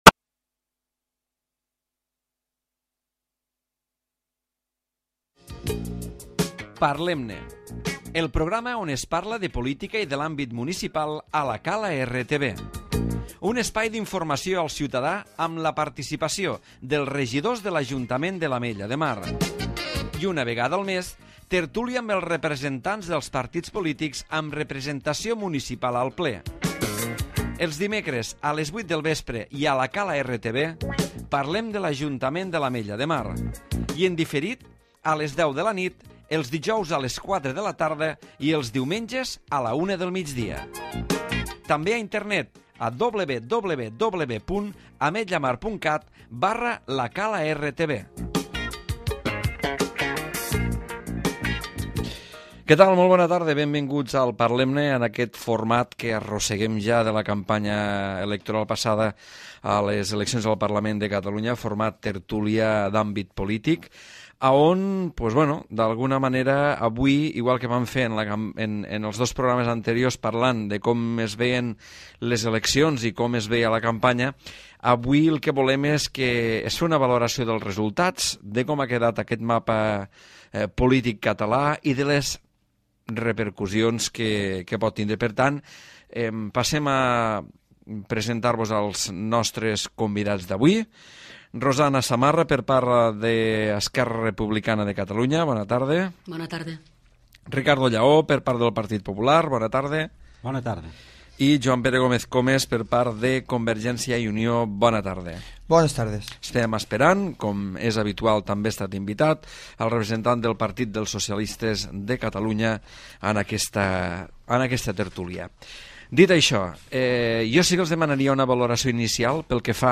El programa Parlem-ne ha abordat el resultat de les eleccions al Parlament de Catalunya del passat 28 de novembre. En format tertúlia política hi han participat els representants dels partits polítics amb representació municipal.